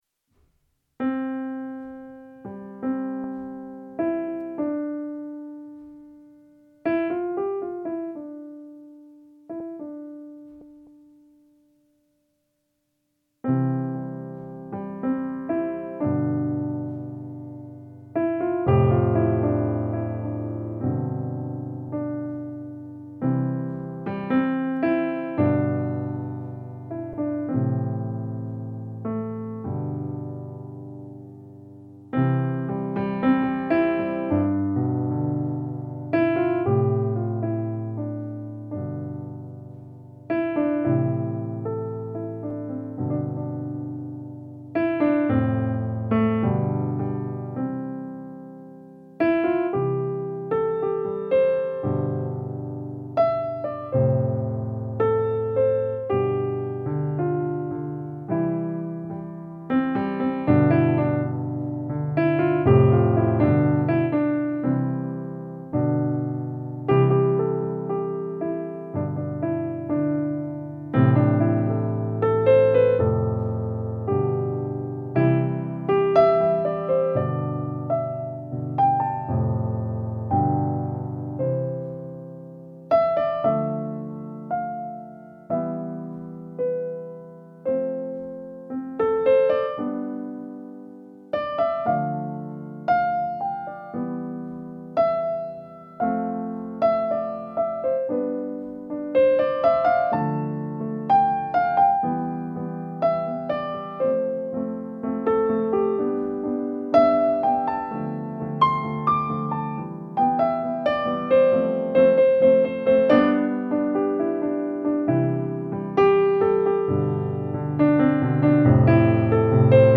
Despite brief moments of doubt, the music reassured and inspired a sense of hope and resilience.
My Feelings Playing the live piano session Fortune in Our Lives was a truly uplifting experience. From the very first note, I felt an incredible surge of positive energy, as if the music was radiating pure optimism.
The music quickly reassured me, guiding me back to a place of confidence and peace.